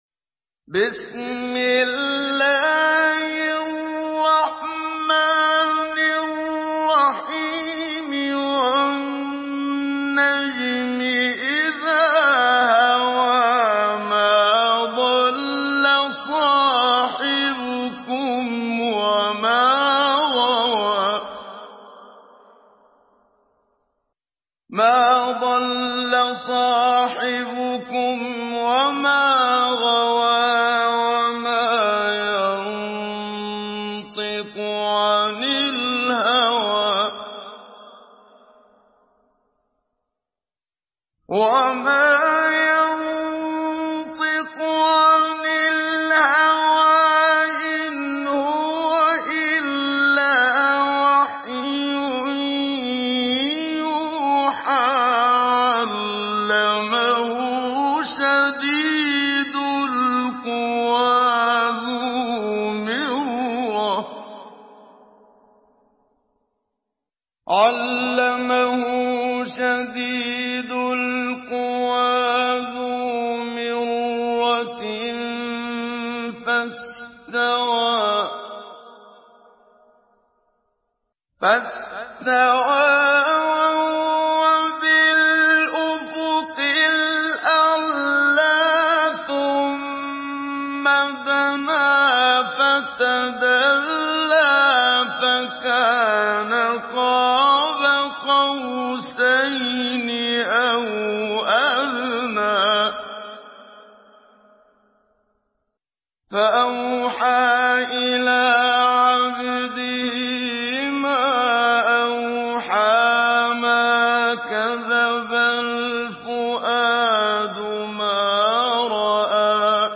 Sourate An-Najm MP3 à la voix de Muhammad Siddiq Minshawi Mujawwad par la narration Hafs
Une récitation touchante et belle des versets coraniques par la narration Hafs An Asim.